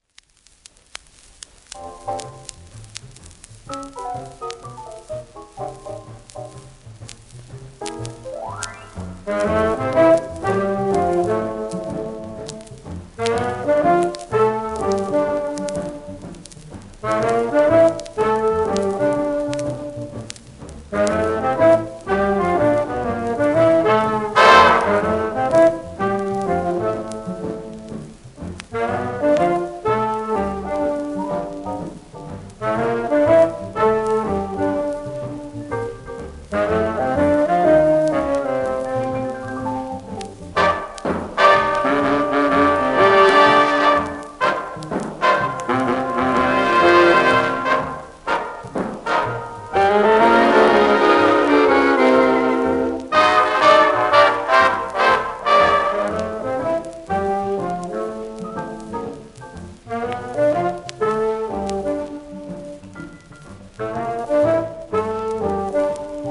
w/オーケストラ
戦中に米国が軍向けに製作したレコード。